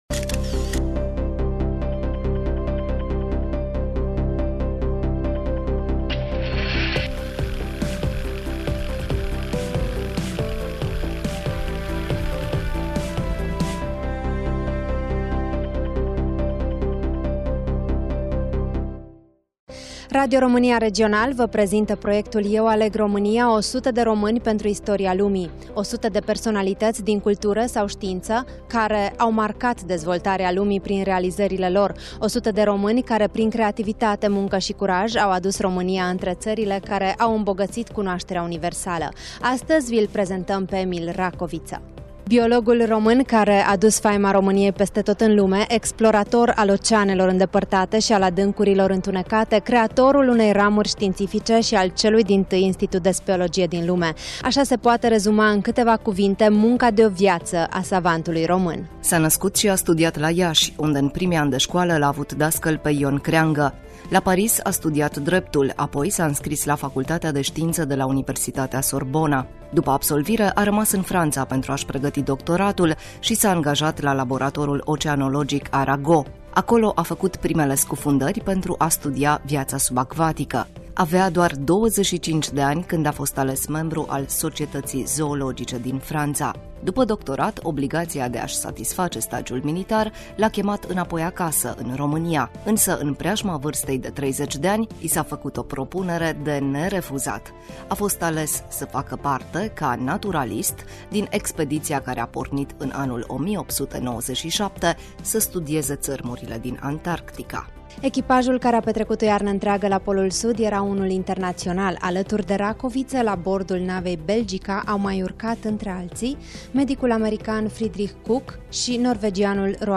Studioul: Radio Romania Cluj